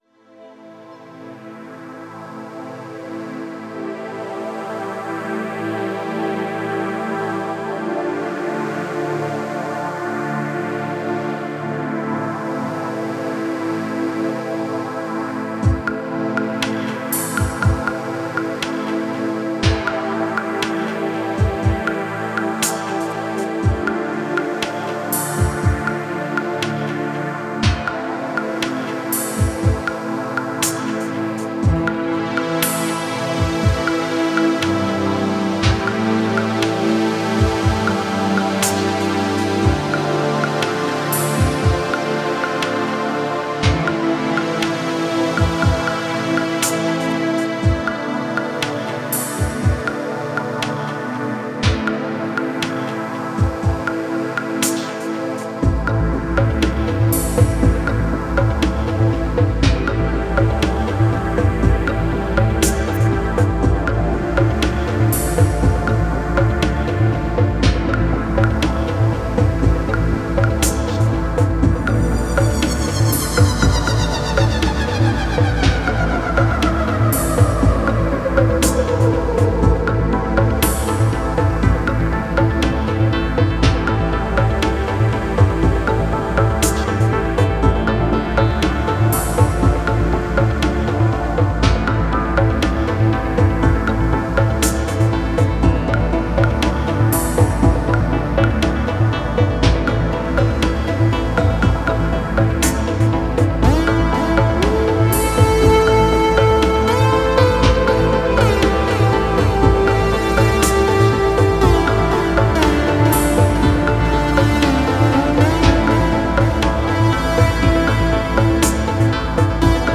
Musik für atmosphärische Abendstunden!
Synthesizersounds zum Relaxen!